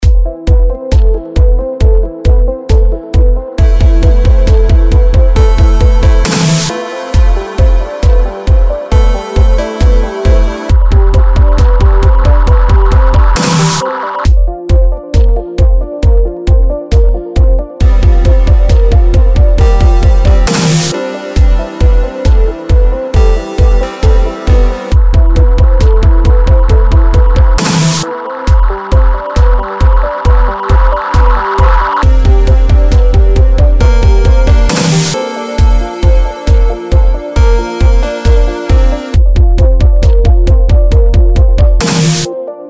• Качество: 320, Stereo
громкие
Electronic
электронная музыка
спокойные
без слов
Electronica